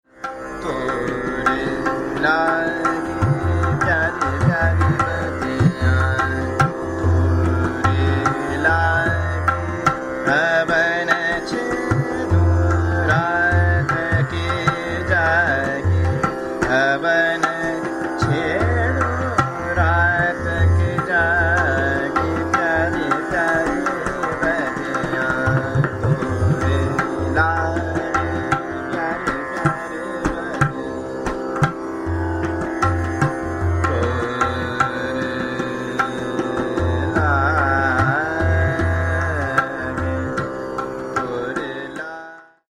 Aroha: NrGmMm, NS
Avroh: SNMm, mGrS
• Tanpura: Sa–ma